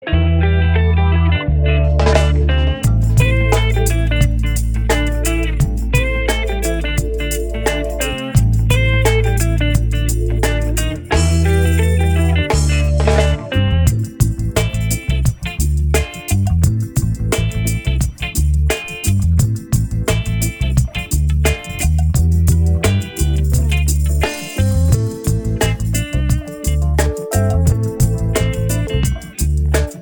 Genre: Voiceless